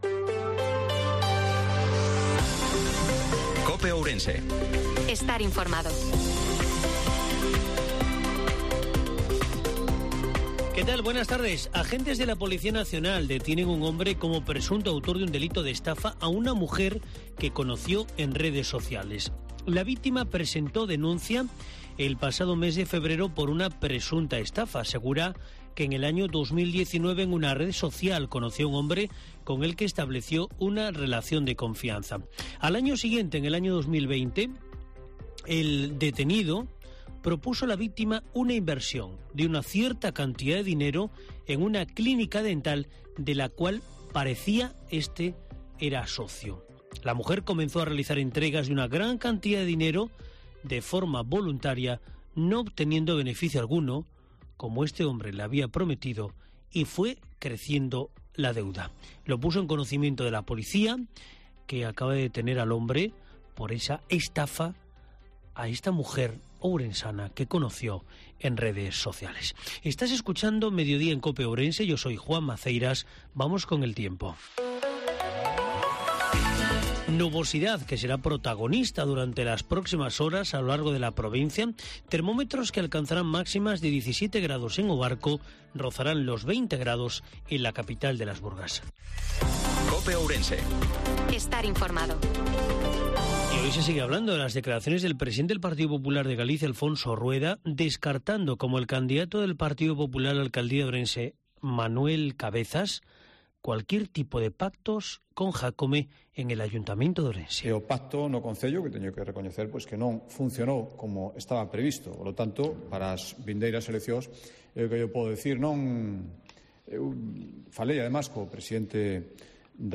INFORMATIVO MEDIODIA COPE OURENSE-31/03/2023